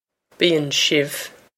Pronunciation for how to say
bee-on shiv
This is an approximate phonetic pronunciation of the phrase.